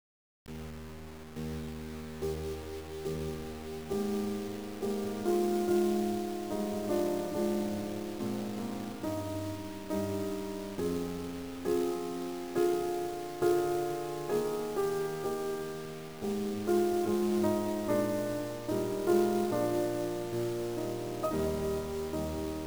To examine the performance of the non-linear compression, I will use a real (piano music) example to showcase the effects of linear/non-linear dynamic compression.
5 bit (which sounds like footsteps in the snow …):
bach_kurtag_5bit.wav